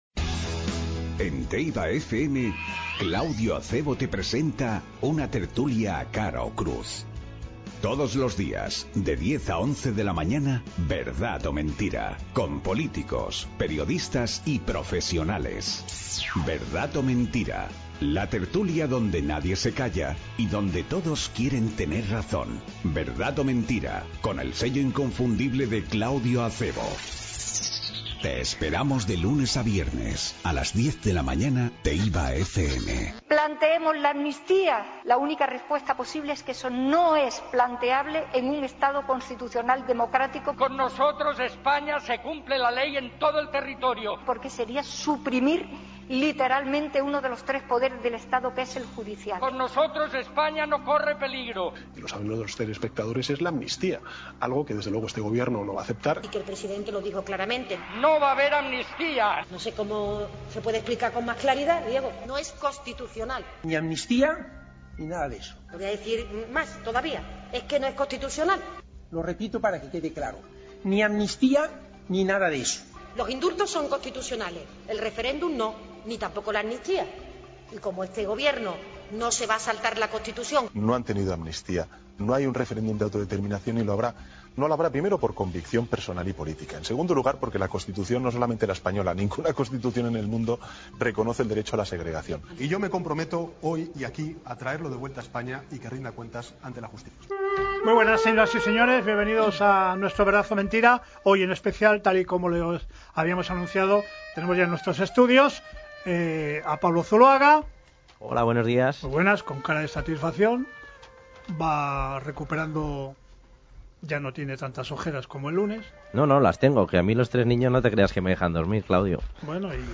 Verdad o Mentira 'Entrevista a Pablo Zuloaga'